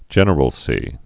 (jĕnər-əl-sē)